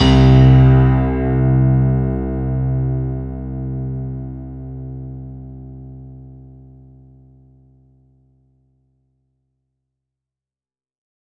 C1  DANCE -L.wav